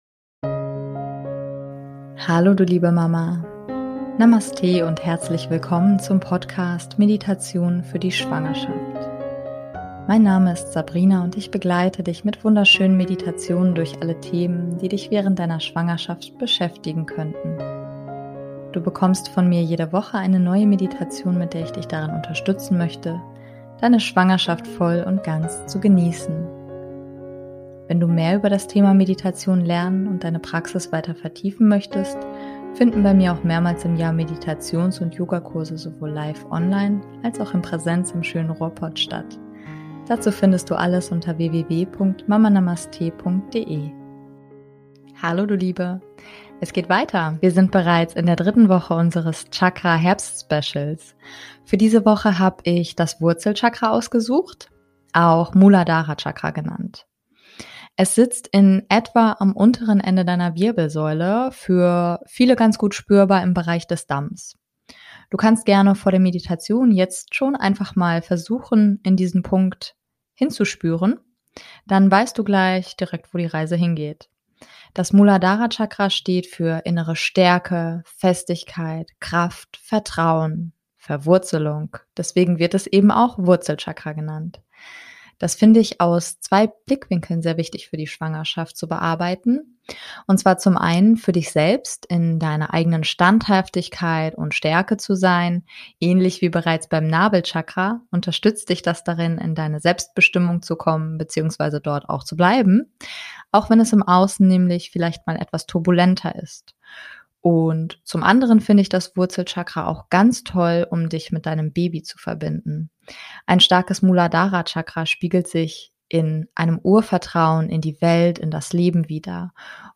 #058 - Meditation - Wurzel Chakra - Für Schwangere ~ Meditationen für die Schwangerschaft und Geburt - mama.namaste Podcast